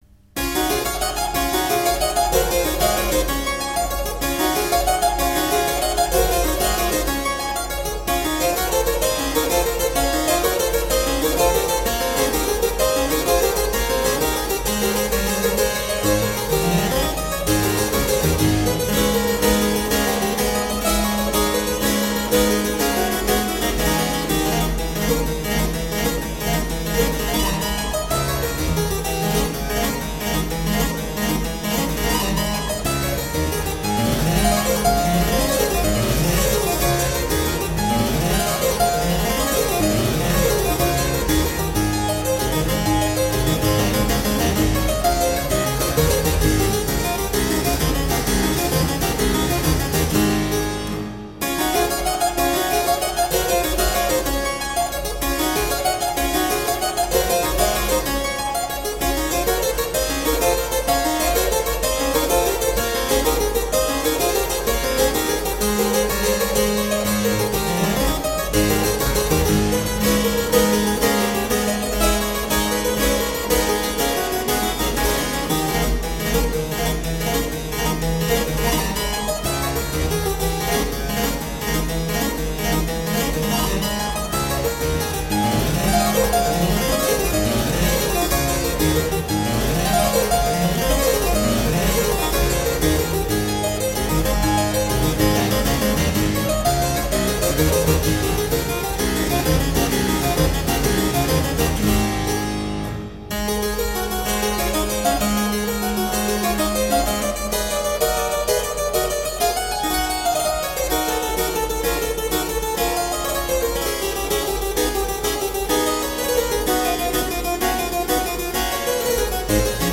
Sonate pour clavecin Kk 492 : Presto